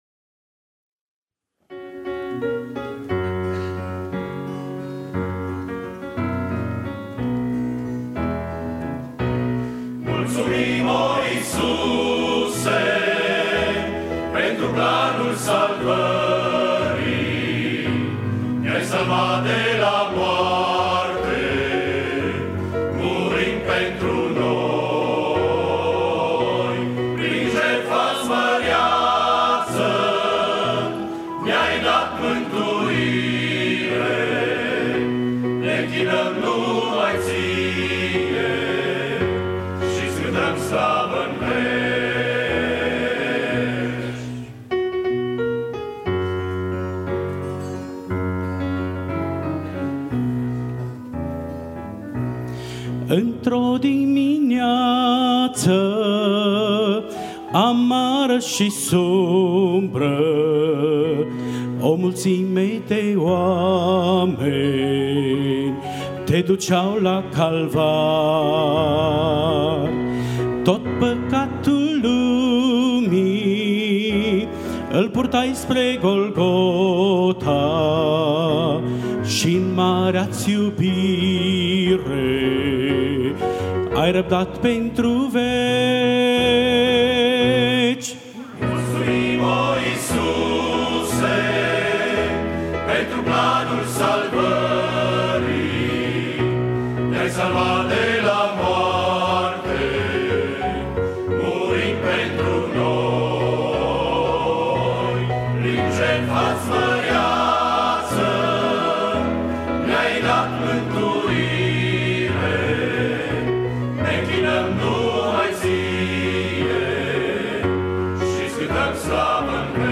04 Corul barbatesc.mp3